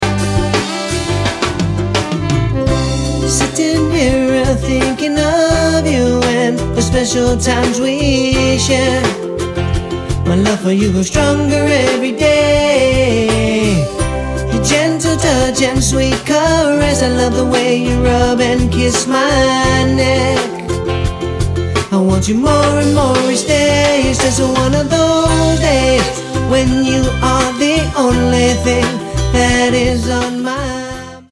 Genre: Contemporary, Jawaiian, world music.